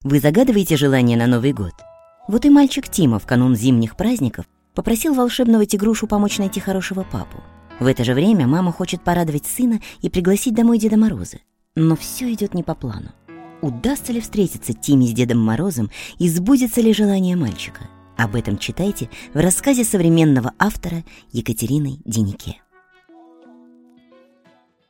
Аудиокнига Чудо на мягких лапах | Библиотека аудиокниг